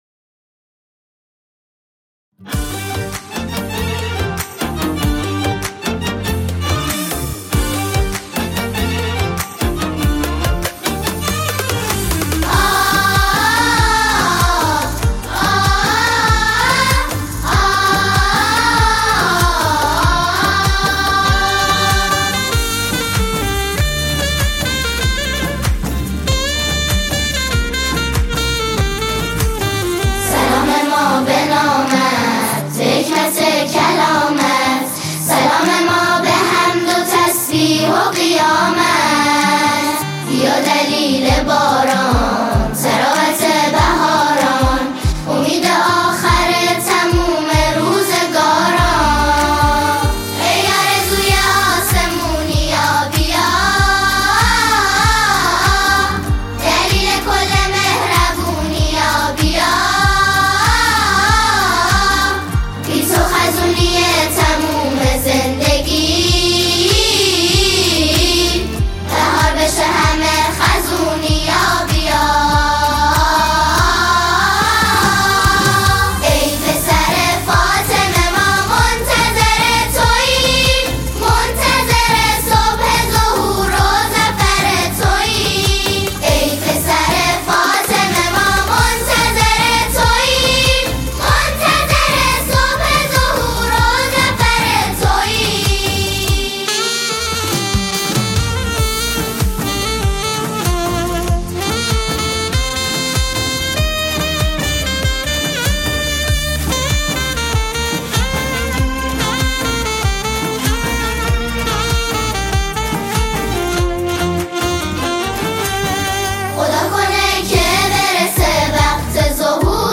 ژانر: سرود ، سرود مذهبی ، سرود مناسبتی